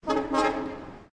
honk_2x.ogg